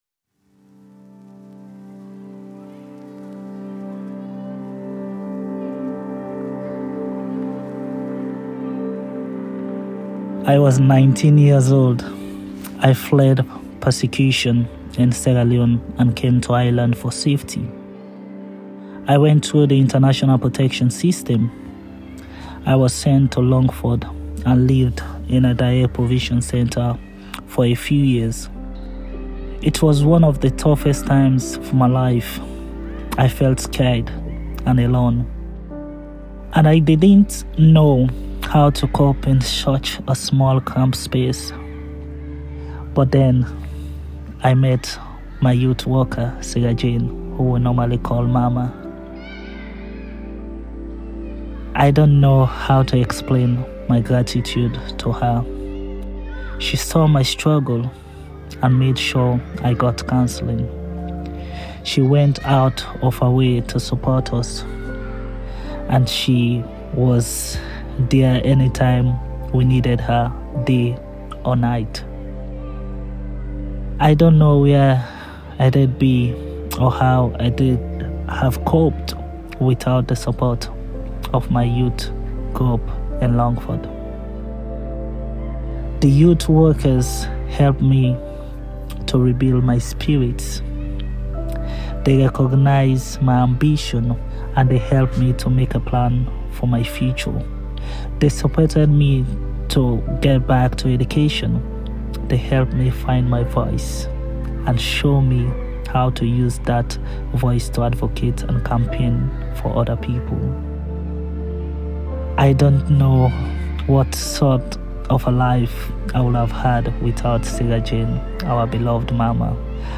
🔊 Stories of Youth Work Soundscape Experience
Have a listen to the immersive soundscape experience that set the tone for the day – emotive stories from young people and youth workers alike about how youth work changed their lives.